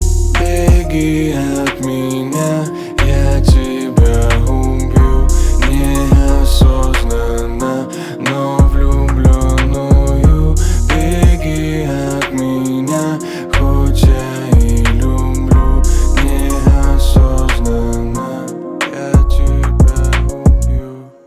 русский рэп , грустные